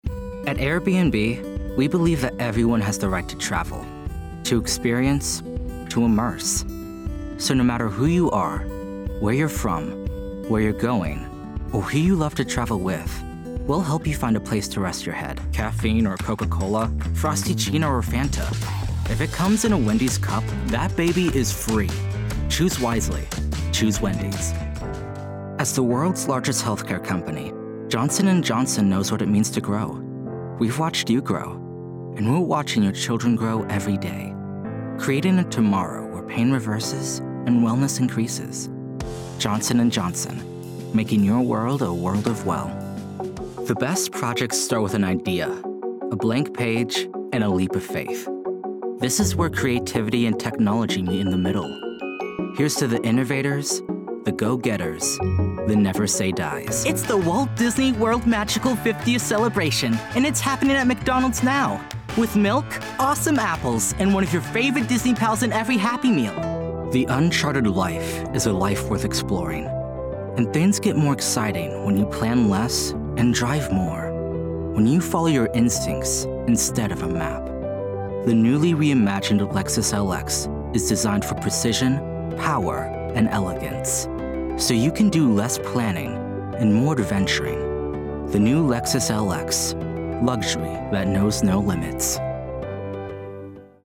Teenager, Young Adult, Adult
Has Own Studio
standard us | natural
COMMERCIAL 💸
conversational
quirky
warm/friendly